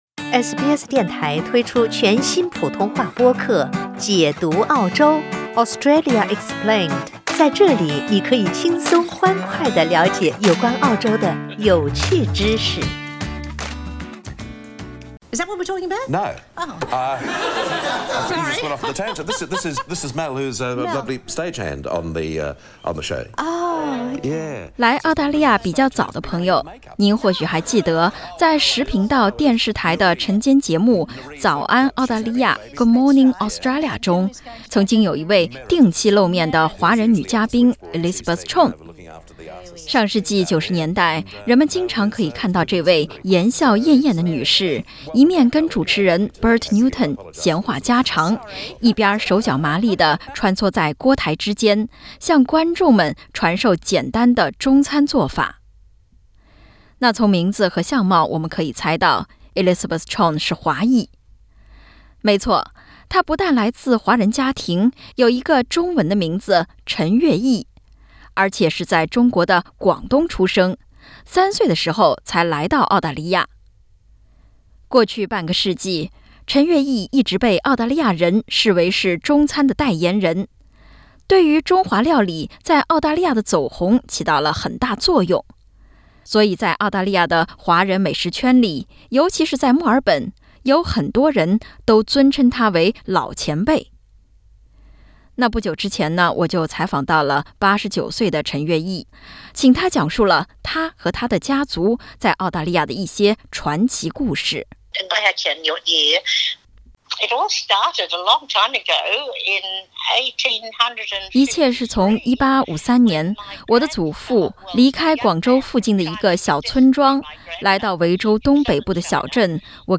她和她的家族见证了中餐及其背后的华人文化在澳大利亚逐渐被社会接受的那段历史。点击音频收听SBS中文对陈月意的独家采访。